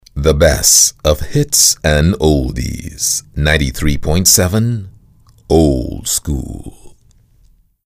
Englisch (Karibik)
Neumann U87
BaritonBassNiedrig
VertrauenswürdigUnternehmenAutorisierendSamtig